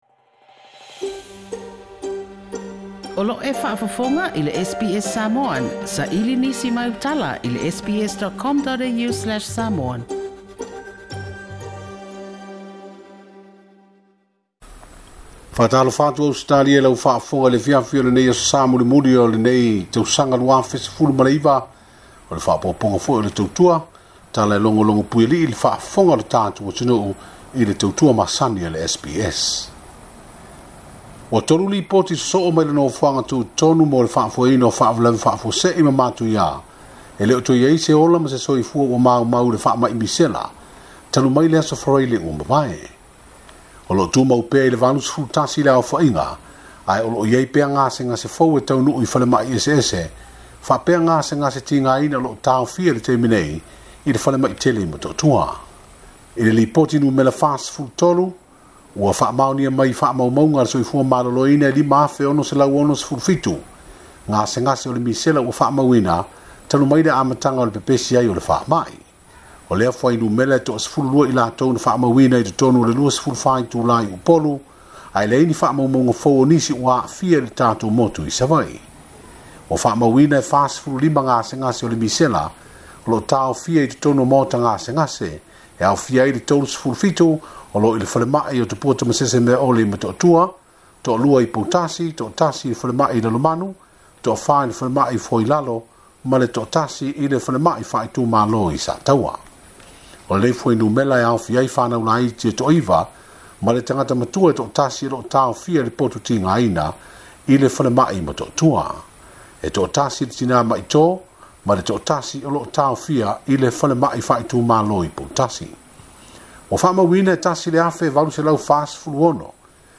Faafofoga ise lipoti lata mai o le misela i Samoa